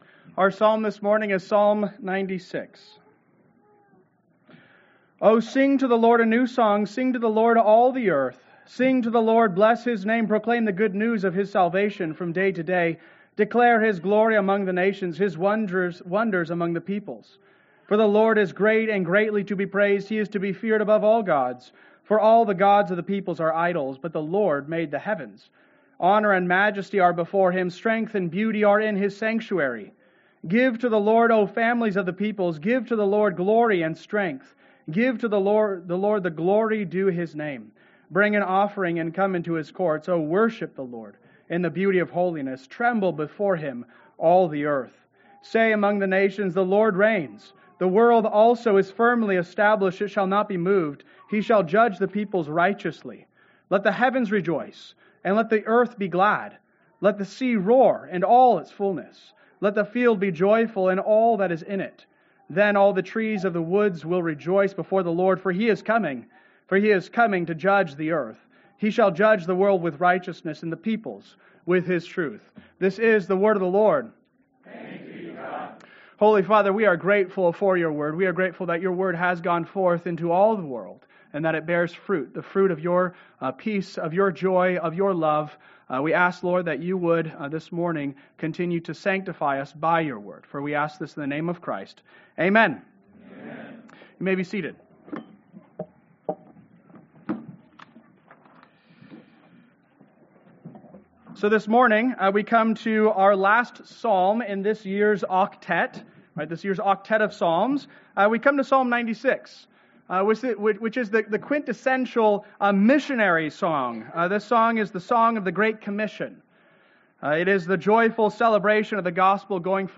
Sermon Outline: 01-19-25 Outline Jesus in the Psalms (Psalm 96, “Great Commission”)